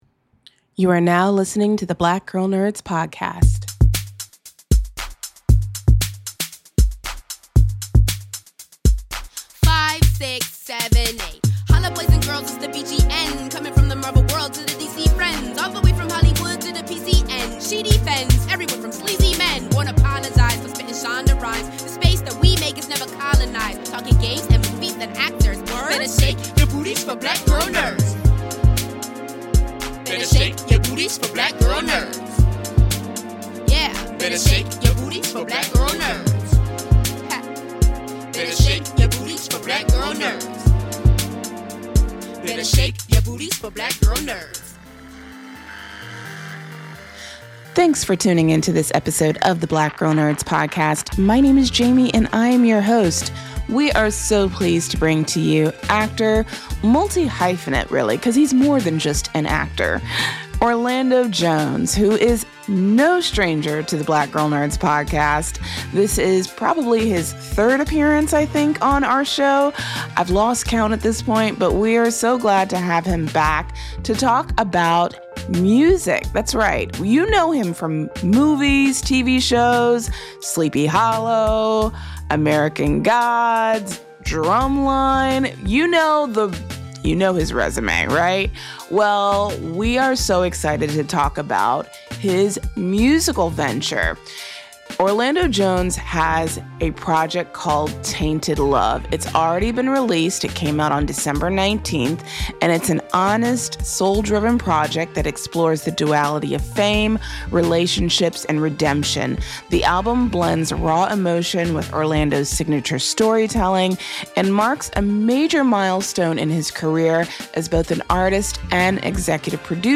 So sit back, relax, and enjoy the conversation.